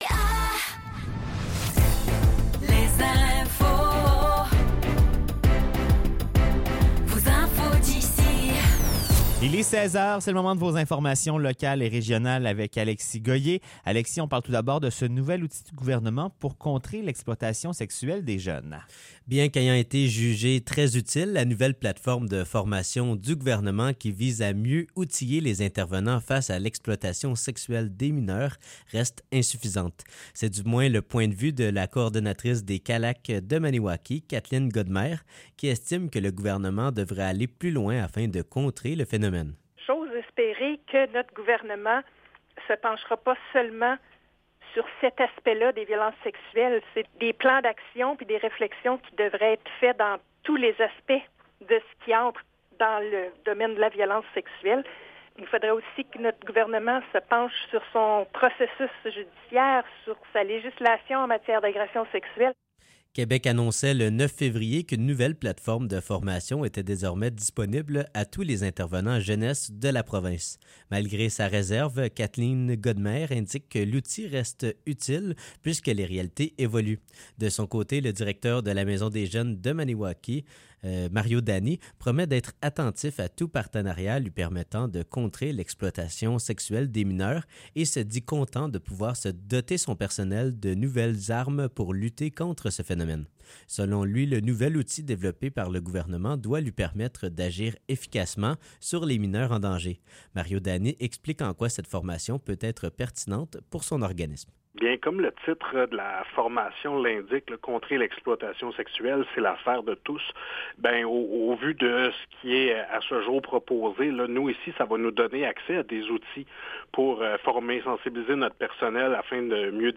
Nouvelles locales - 12 février 2024 - 16 h